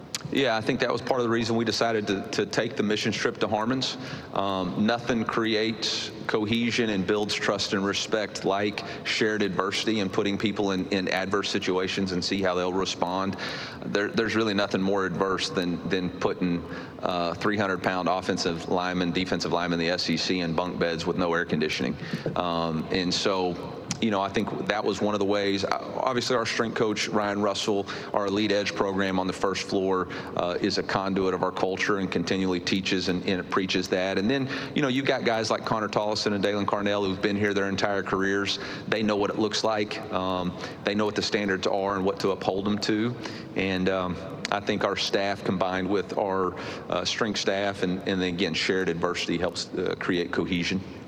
Mizzou head coach Eli Drinkwitz at SEC Media Day